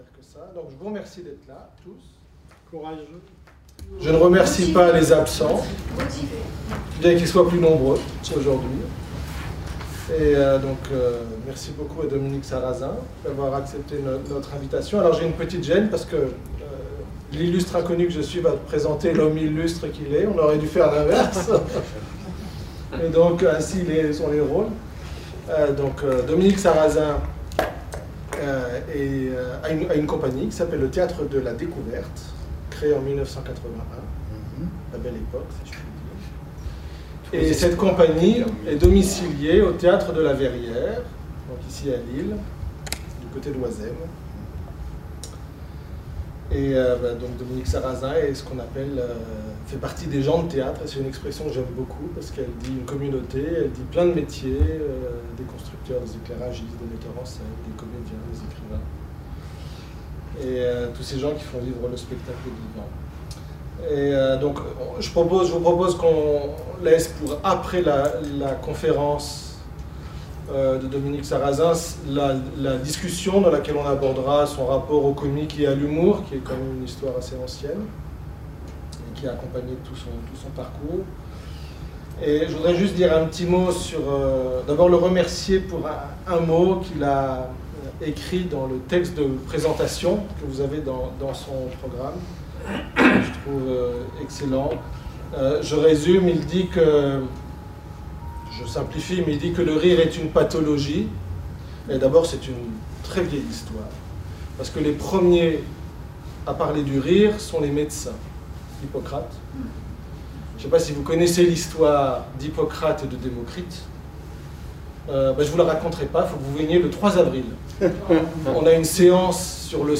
Conférence-spectacle suivie d'une discussion.